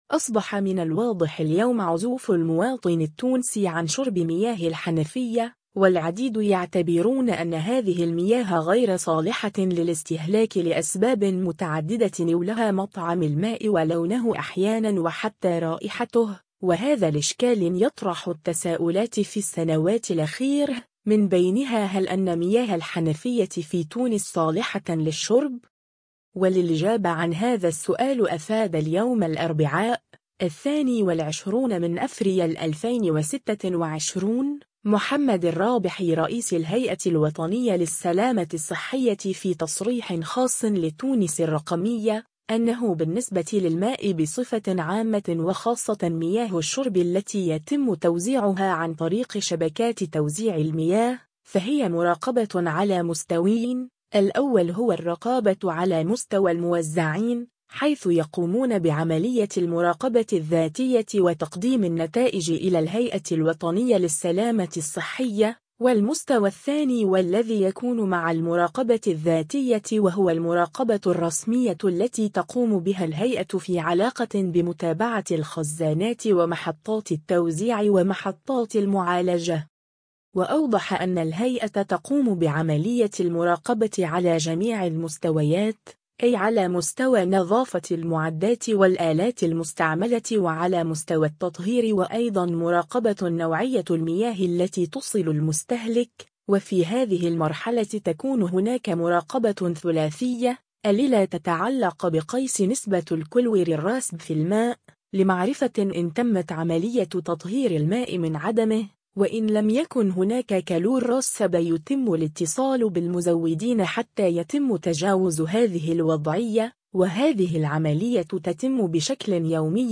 و للاجابة عن هذا السؤال أفاد اليوم الأربعاء، 22 أفريل 2026، محمد الرّابحي رئيس الهيئة الوطنيّة للسلامة الصّحية في تصريح خاص لتونس الرّقمية، أنّه بالنسبة للماء بصفة عامة و خاصة مياه الشّرب التي يتمّ توزيعها عن طريق شبكات توزيع المياه، فهي مراقبة على مستويين، الأول هو الرّقابة على مستوى الموزّعين، حيث يقومون بعملية المراقبة الذّاتية و تقديم النتائج إلى الهيئة الوطنية للسلامة الصّحية، و المستوى الثاني و الذّي يكون مع المراقبة الذّاتية و هو المراقبة الرّسمية التي تقوم بها الهيئة في علاقة بمتابعة الخزانات و محطّات التوزيع و محطّات المعالجة.